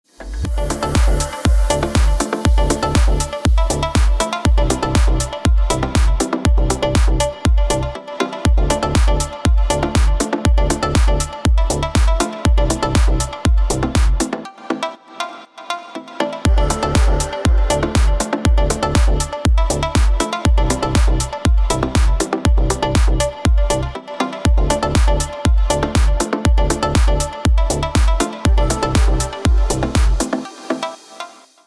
Дип Хаус отрывки на рингтон